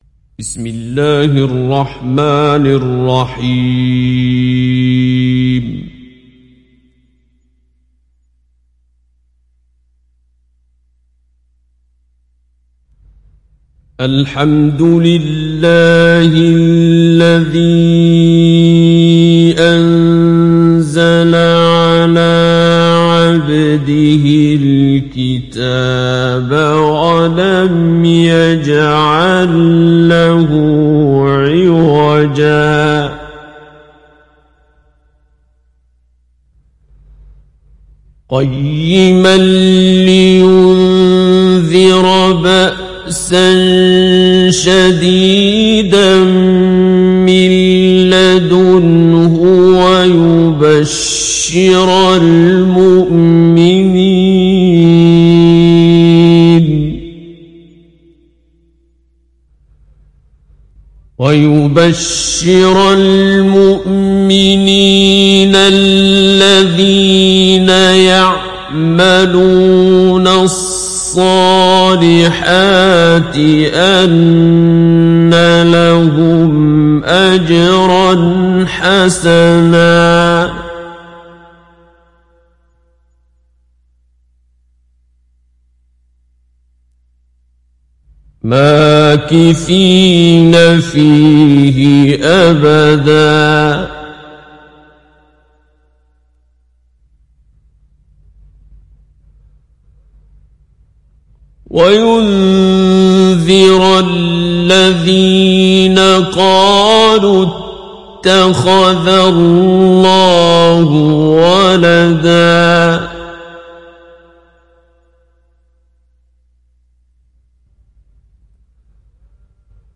تحميل سورة الكهف mp3 بصوت عبد الباسط عبد الصمد مجود برواية حفص عن عاصم, تحميل استماع القرآن الكريم على الجوال mp3 كاملا بروابط مباشرة وسريعة
تحميل سورة الكهف عبد الباسط عبد الصمد مجود